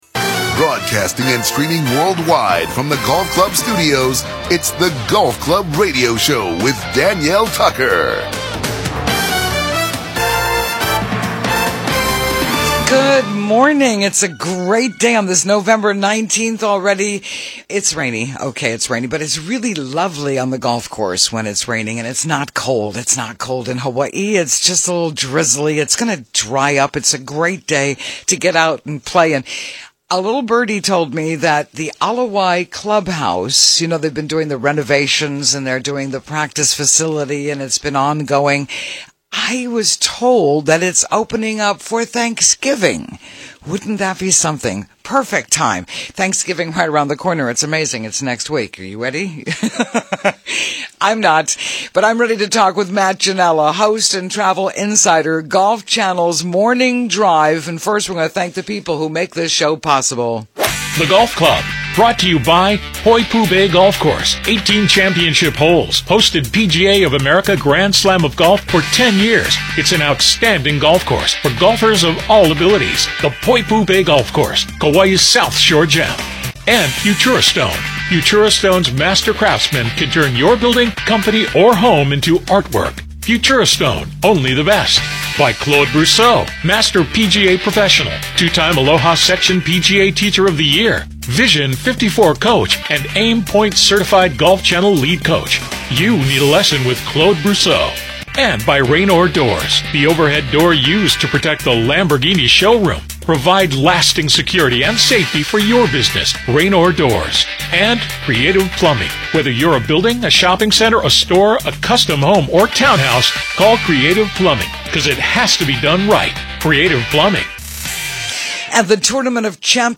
broadcasting state-wide talking to Hawaii's Golf Pros and across America sports shrinks, authors, mental coaches and PGA broadcasters.